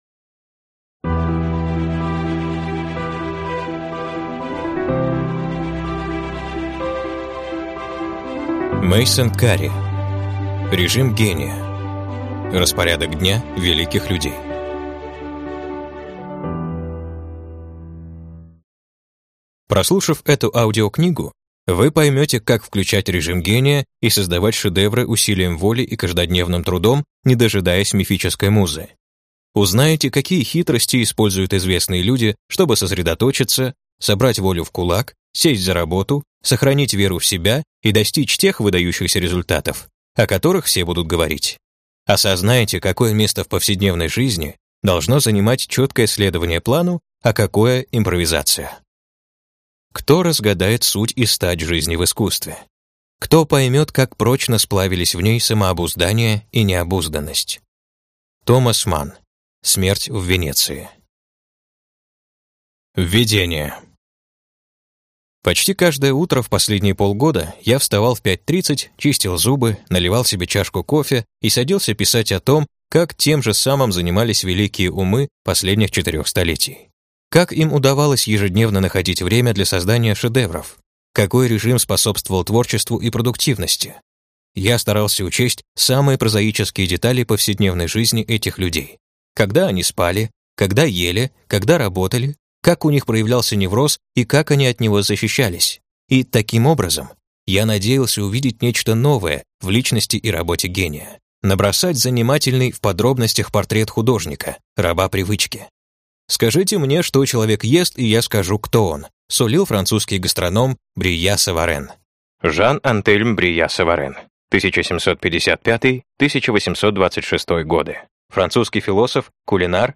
Аудиокнига Режим гения. Распорядок дня великих людей | Библиотека аудиокниг